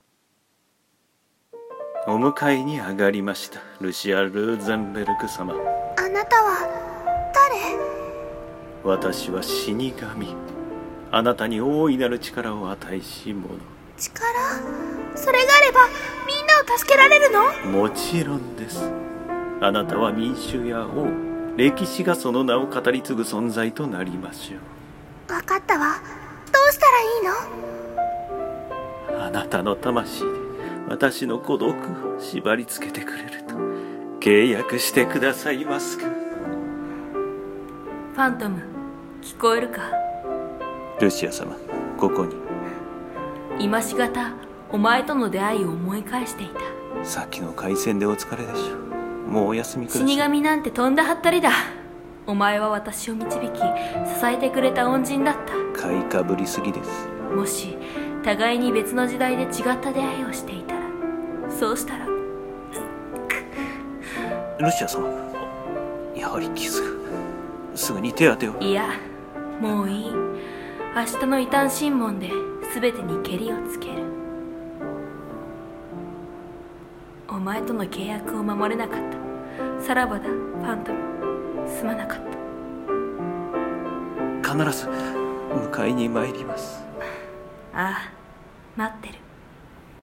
声劇台本「亡霊はトロイメライを捧ぐ」